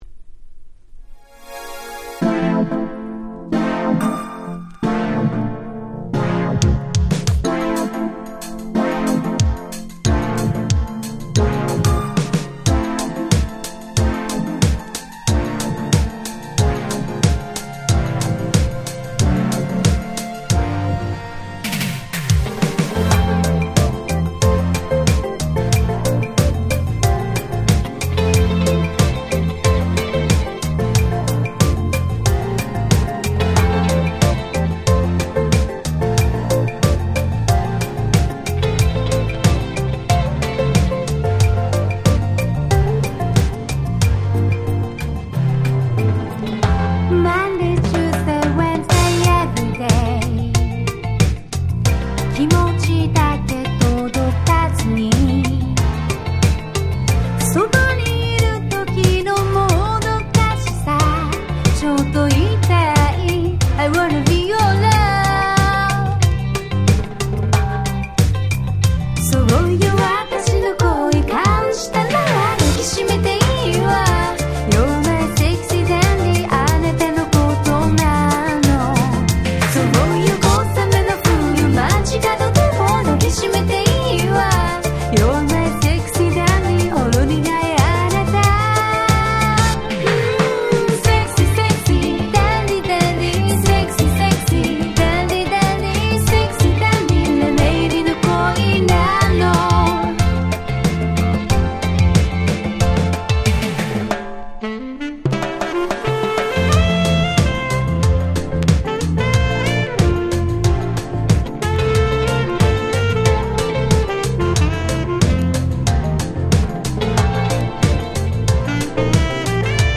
23' Nice City Pop / Japanese R&B !!